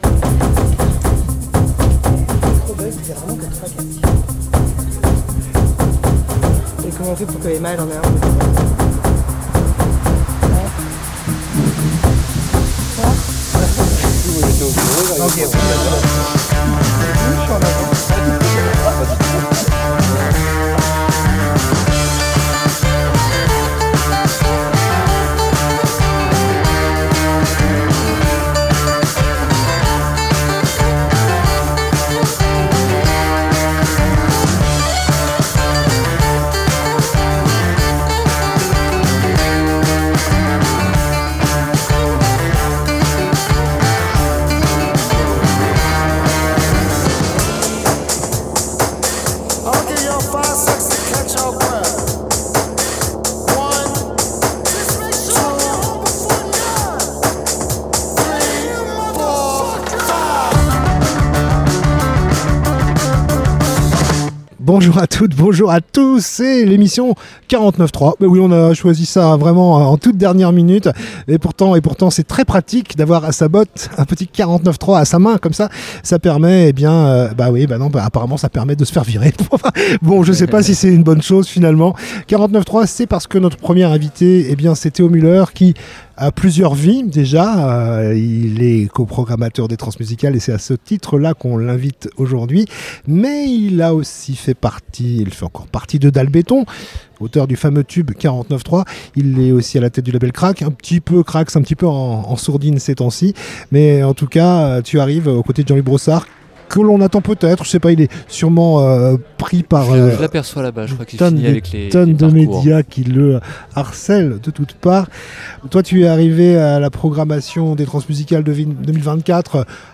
Jeudi 5 décembre, c'était la première de trois émissions spéciales réalisées en direct des Trans musicales au Liberté.